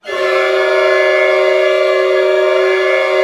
Index of /90_sSampleCDs/Roland LCDP08 Symphony Orchestra/ORC_ChordCluster/ORC_Clusters
ORC CLUST06L.wav